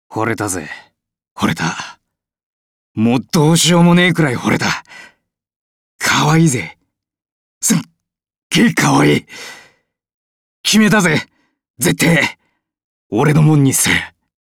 ケン　　　ＣＶ：上田祐司